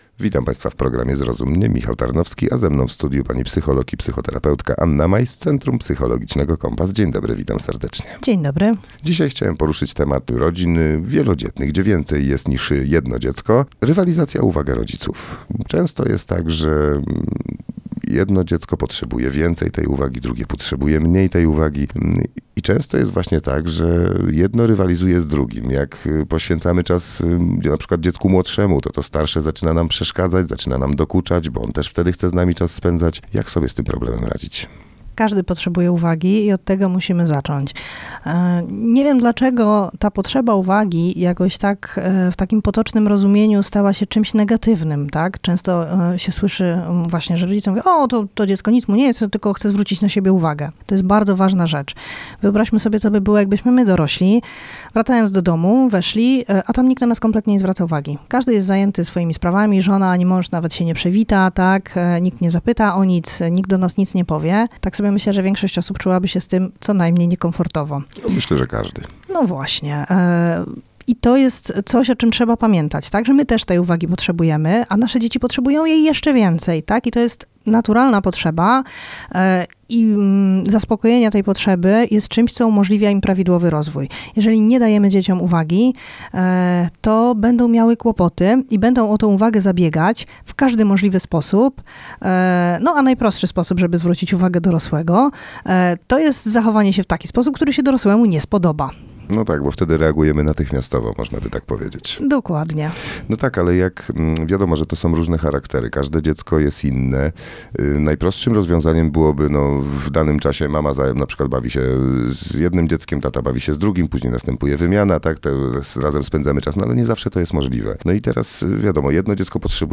Zrozum mnie – program parentingowy na antenie Radia Radom